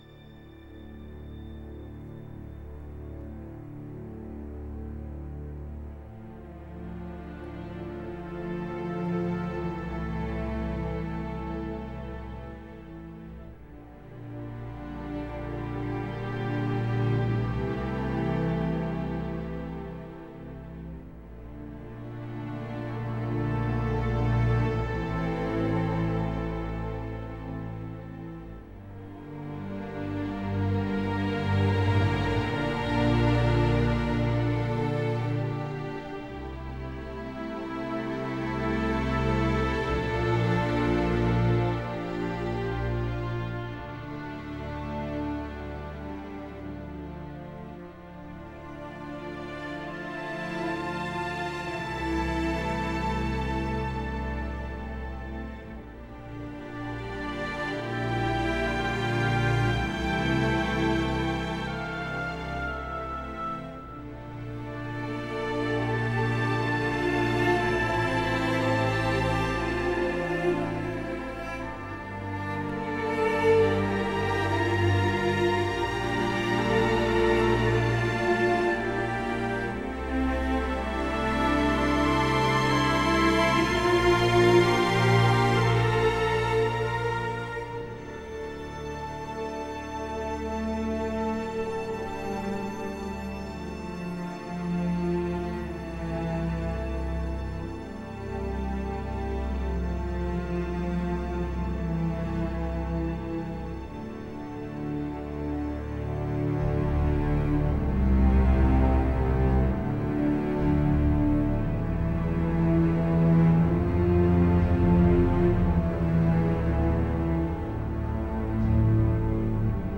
موسیقی متن موسیقی بیکلام موسیقی حماسی